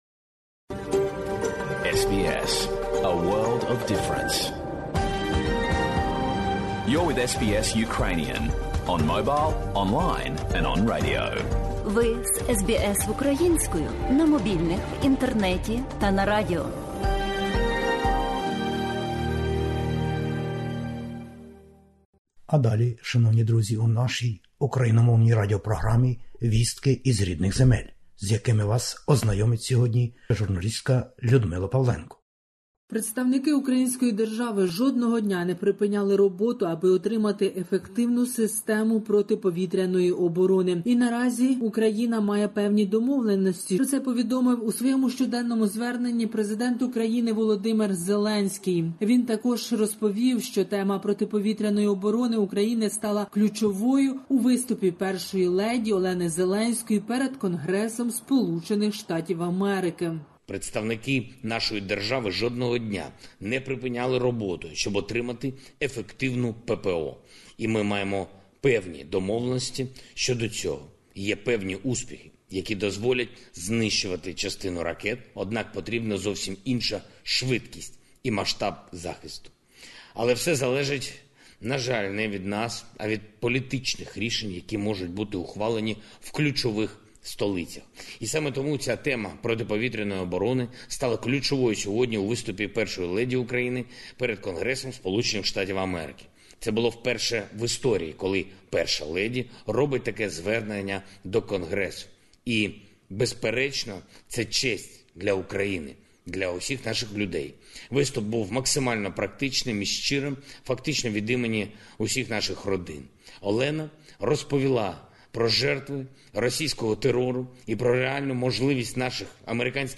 Добірка новин із героїчної України. Війна в Україні - Харків обстріляли з ураганів уранці 20 липня.